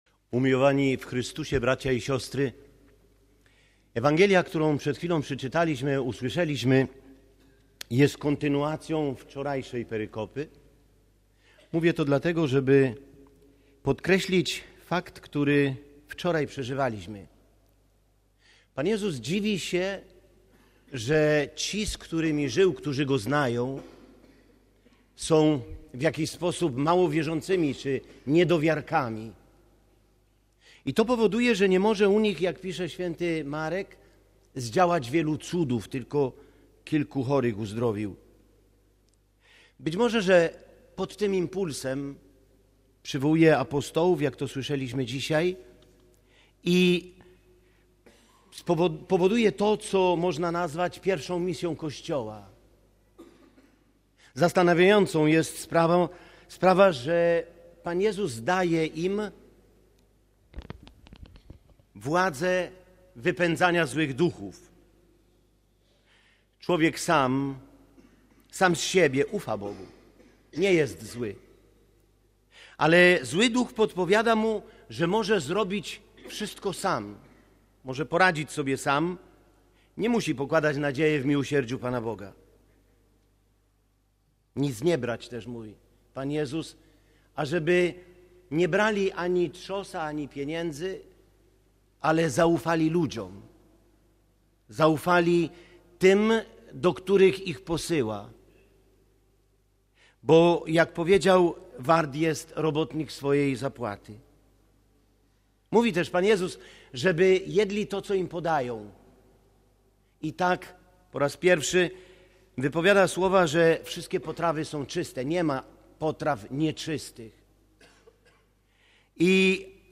Msza przy grobie św. Jana Pawła II - 4 lutego 2016 r.